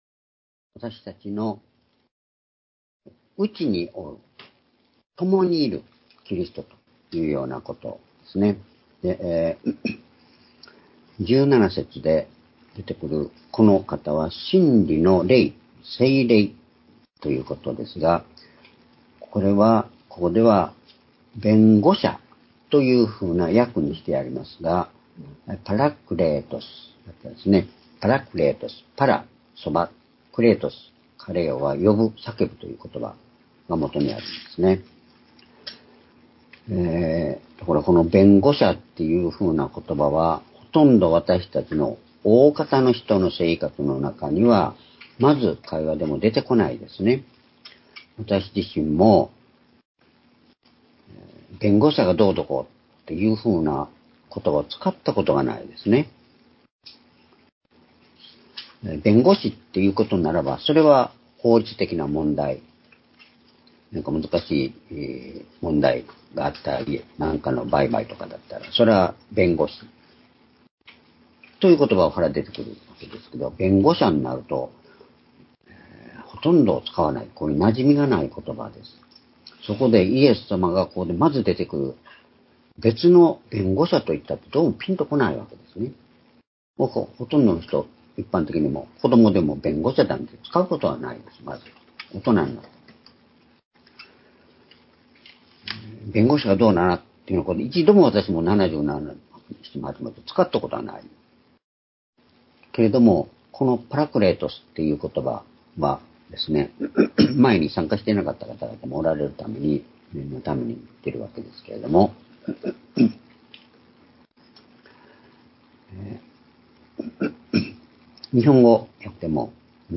主日礼拝日時 2024年3月24日(主日) 聖書講話箇所 「我らの内にいまし、共におられるキリスト」 ヨハネ14章17～19節 ※視聴できない場合は をクリックしてください。